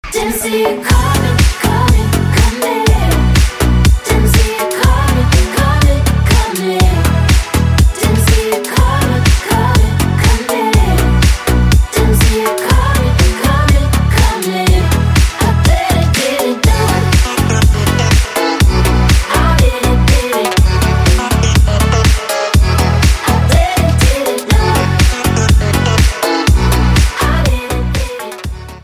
• Качество: 320, Stereo
женский вокал
remix
deep house
Electronic